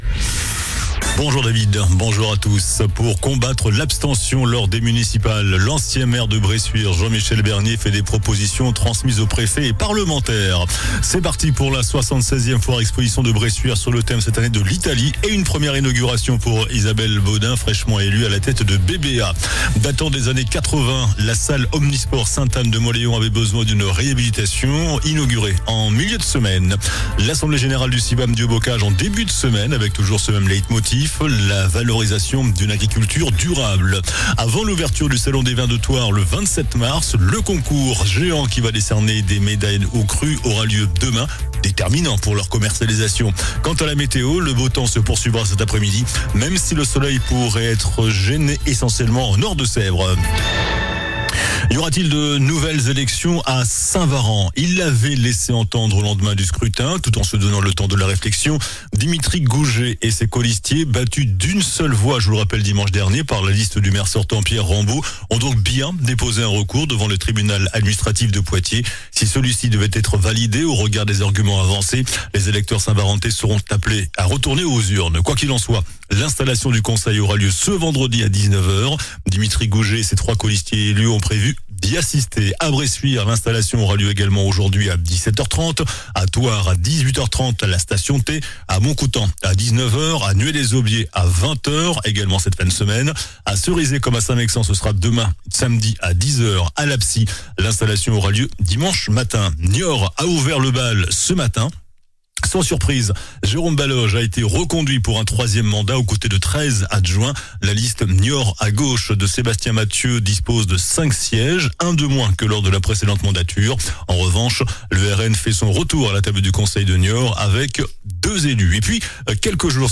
JOURNAL DU VENDREDI 20 MARS ( MIDI )